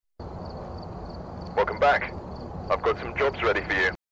phasmaphobia Radio Voice 1
phasmaphobia-radio-voice-1_BNmfIFS.mp3